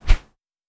Foley Sports / Tennis / Generic Swing Powerful.wav
Generic Swing Powerful.wav